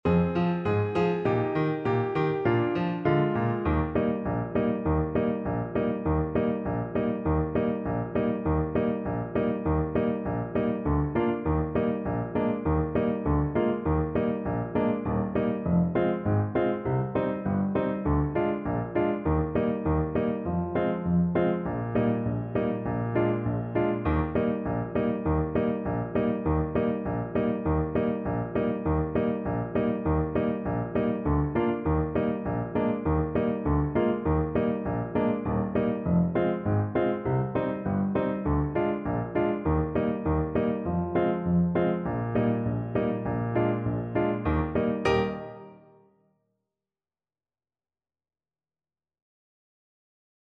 Allegretto
2/4 (View more 2/4 Music)
Bb3-Bb4